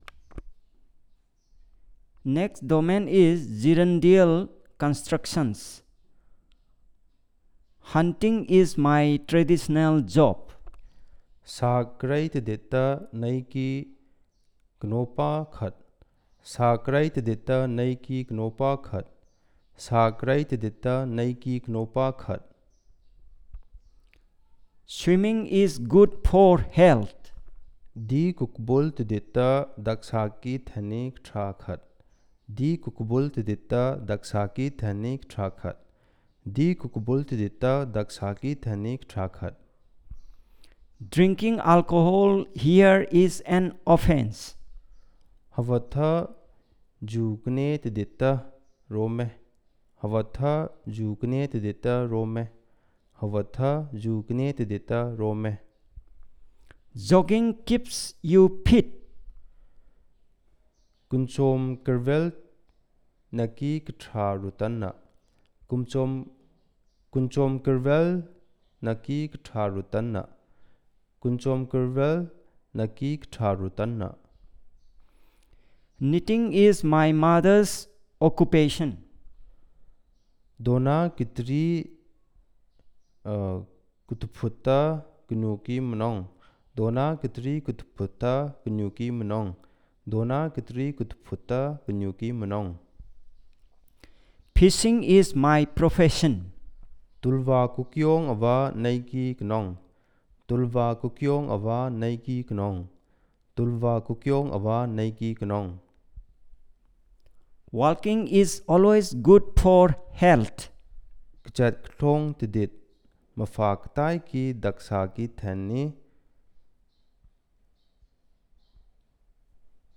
Elicitation of gerundial constructions
TitleElicitation of gerundial constructions